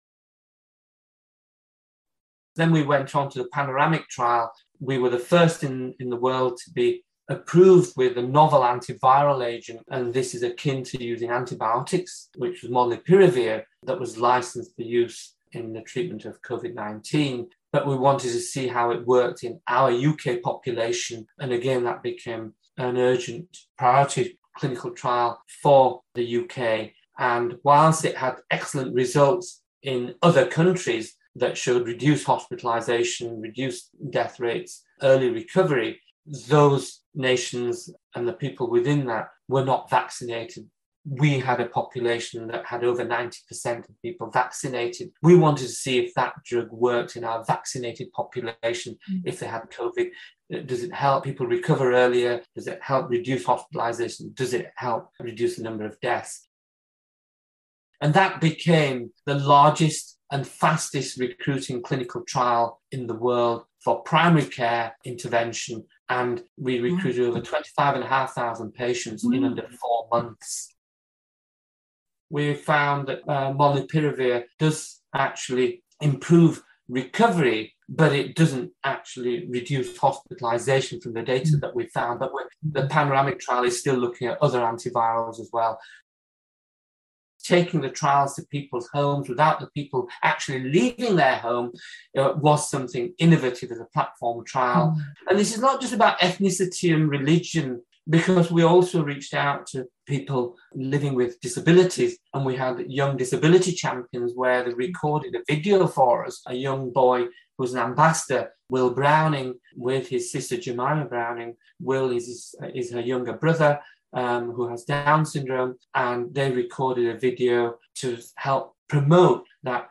RCPharms Museum has a growing collection of oral history recordings where pharmacists past and present share their experiences in their own words.